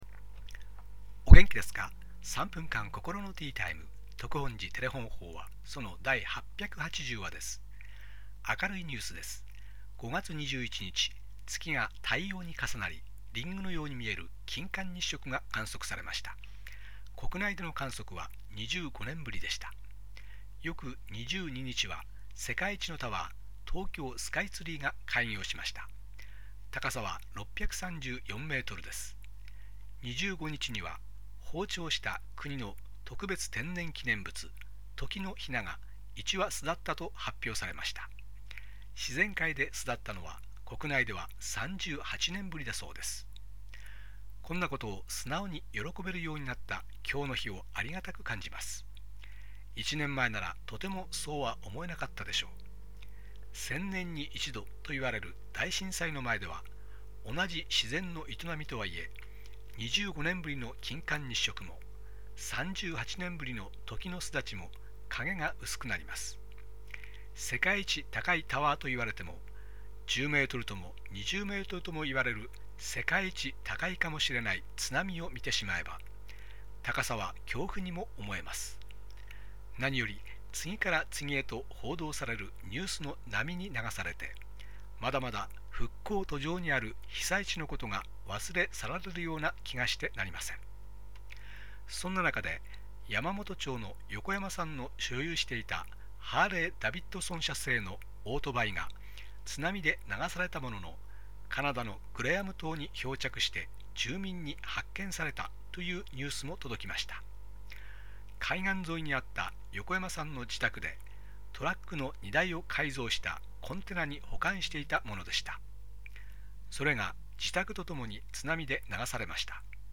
テレホン法話